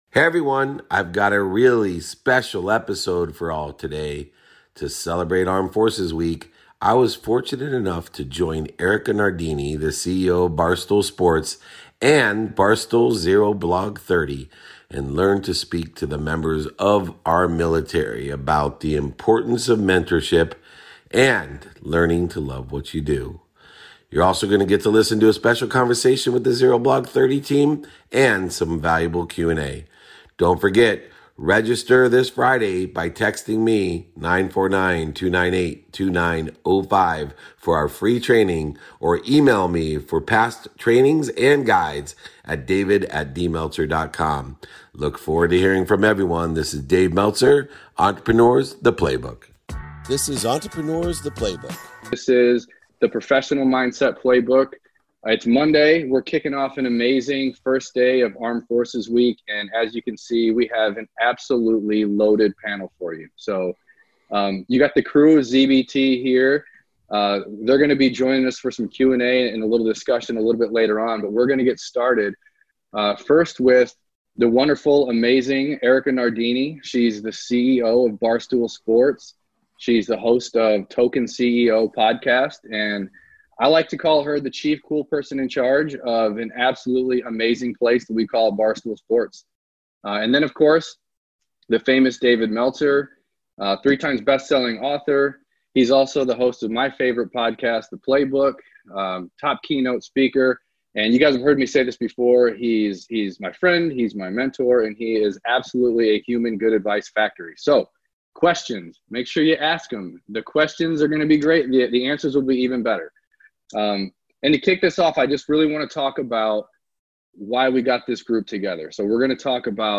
Hey everyone, I’ve got a real special episode for you all today, to celebrate armed forces week I was fortunate to join Erika Nardini, the CEO of Barstool Sports and Barstool’s Zero Blog Thirty team to speak to members of the military about the importance of mentorship and learning to love what you do. You’re also going to get to listen to a special conversation from the Zero Blog Thirty team and some valuable Q&A afterwards.